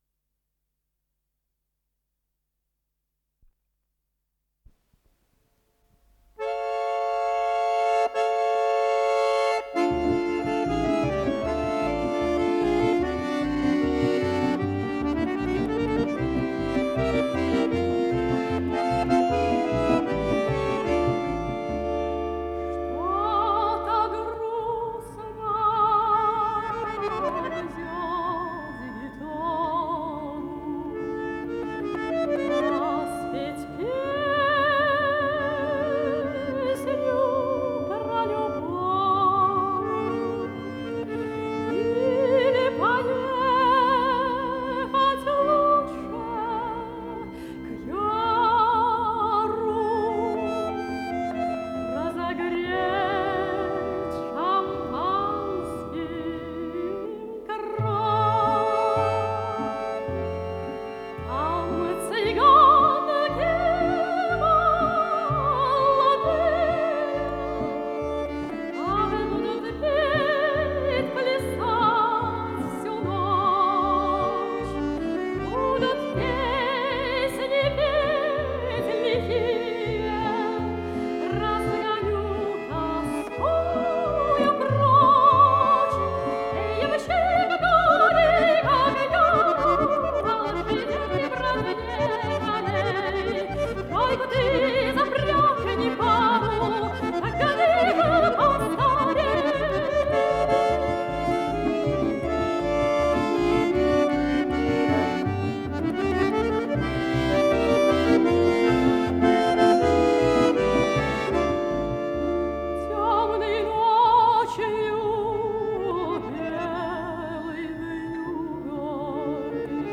с профессиональной магнитной ленты
АккомпаниментИнструментальное трио